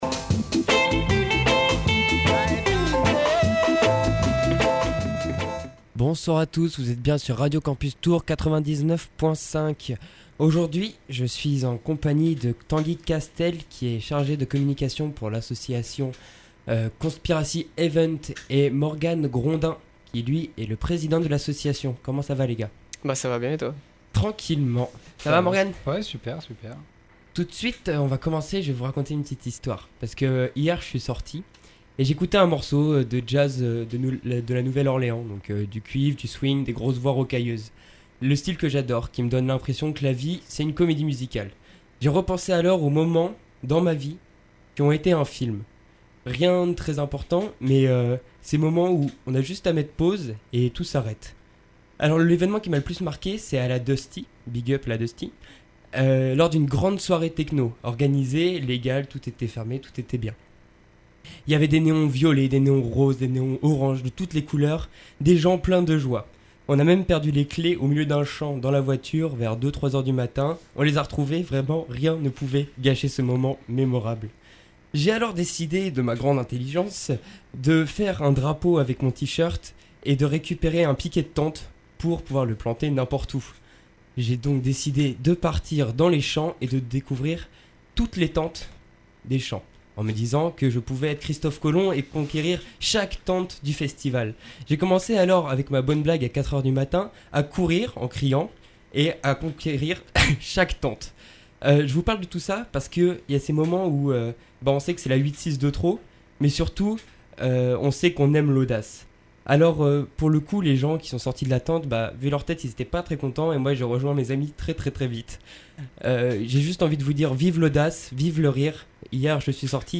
Entretien dans nos studios en vue de la montagne à gravir : La techno parade !